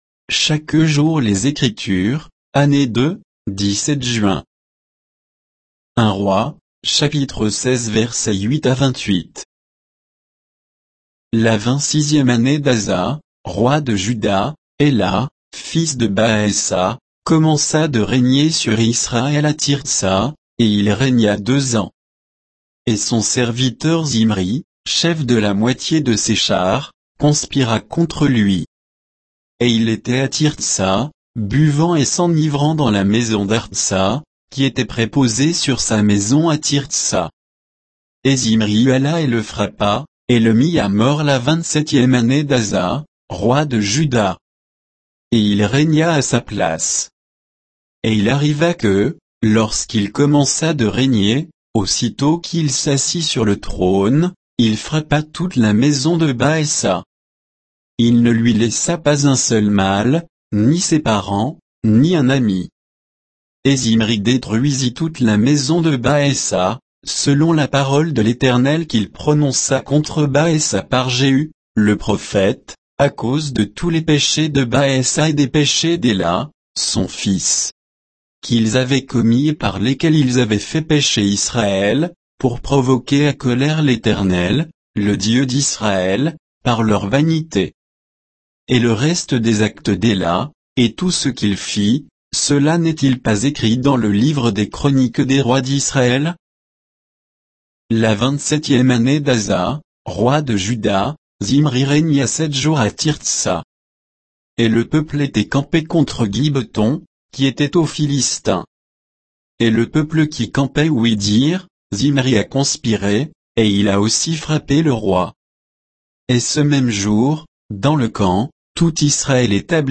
Méditation quoditienne de Chaque jour les Écritures sur 1 Rois 16